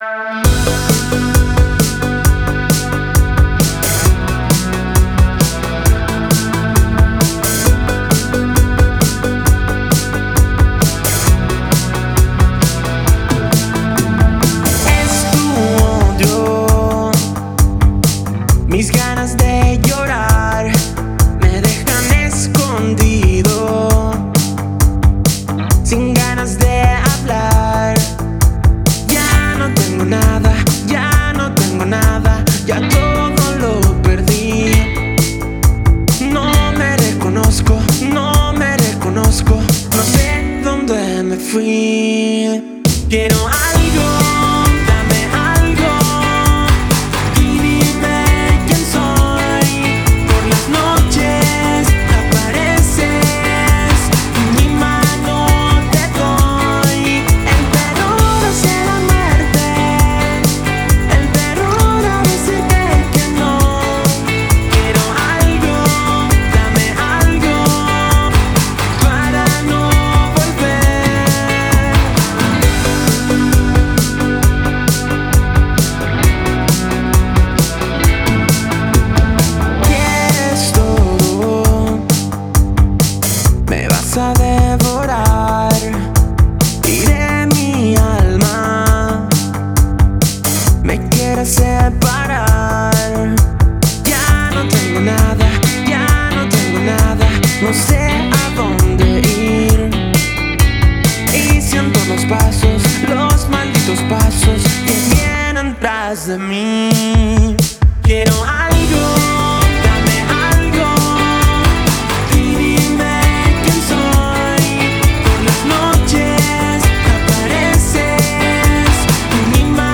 GUITARRISTA (Colaboración)